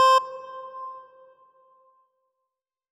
C2.wav